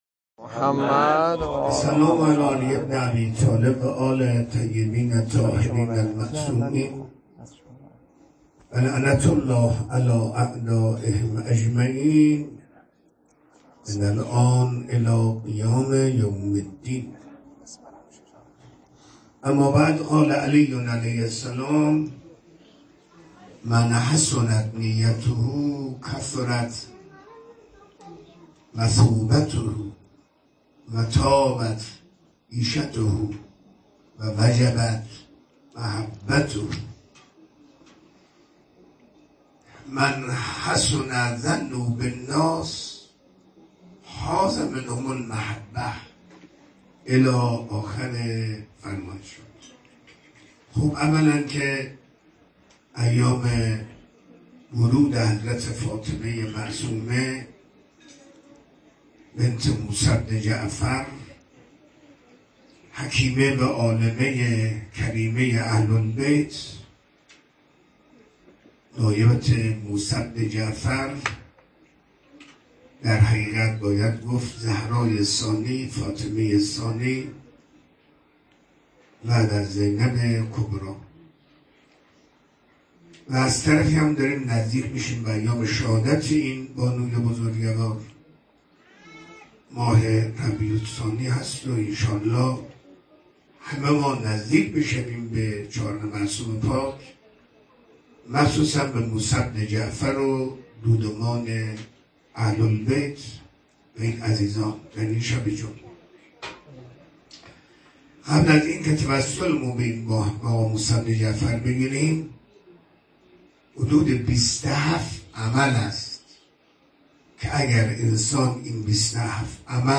منبر 11 مهر 1404، مسجد جوادالائمه (ع)